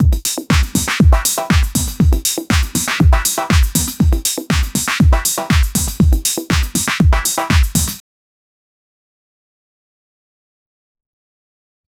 stable-audio-open-small-warm-arpeggios-on-house-beats-120BPM-with-drums-effects.wav